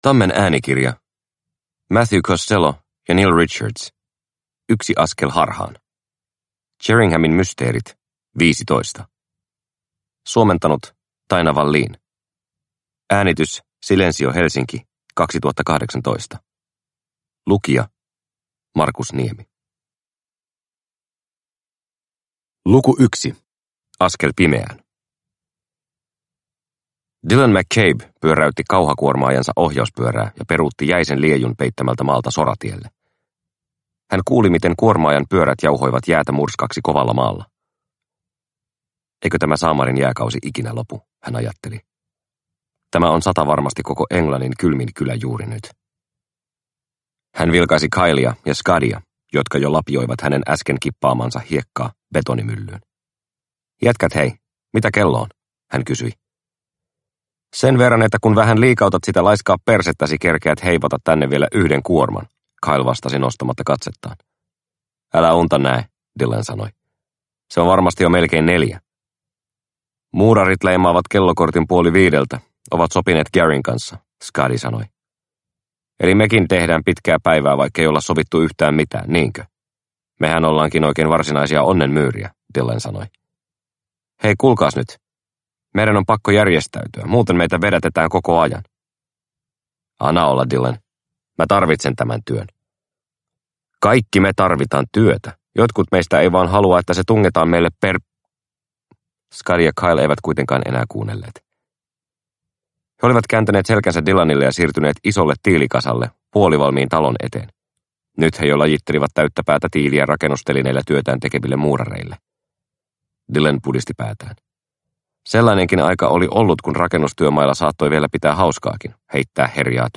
Yksi askel harhaan – Ljudbok – Laddas ner